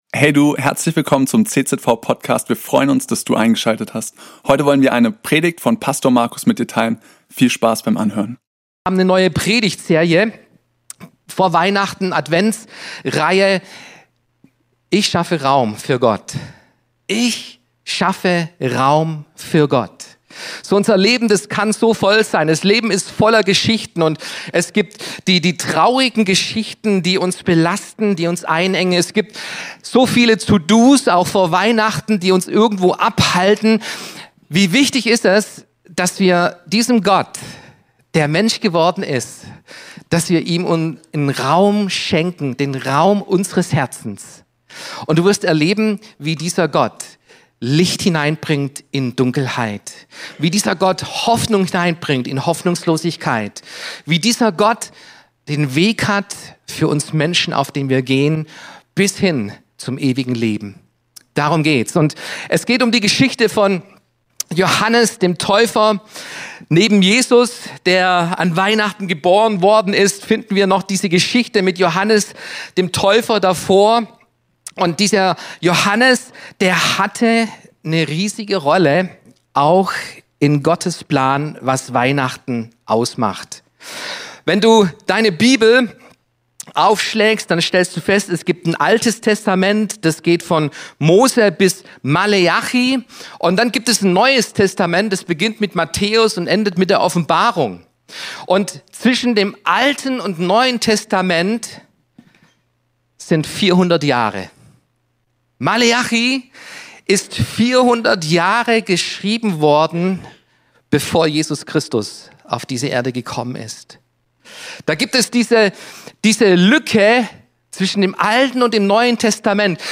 Religion & Spiritualität